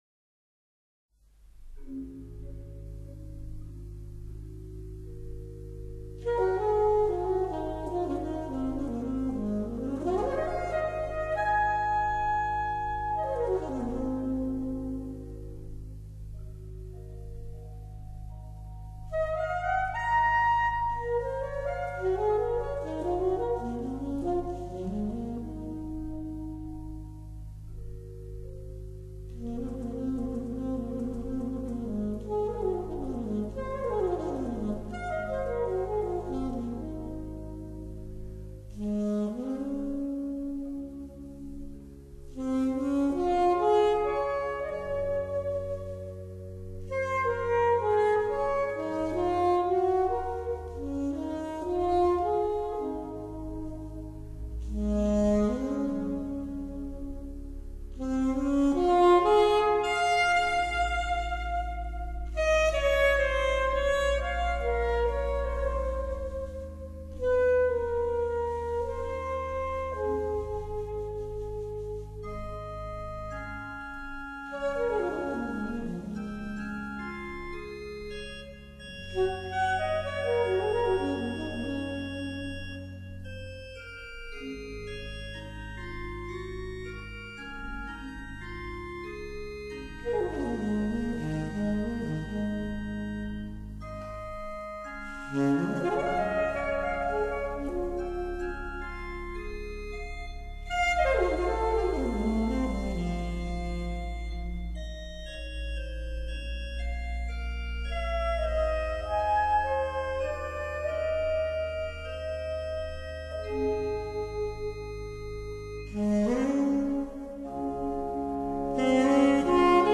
此張專輯只有一隻中音薩克斯風加上管風琴，但是這麽簡單的樂器，卻造就了這一張音樂史上的絕世佳作。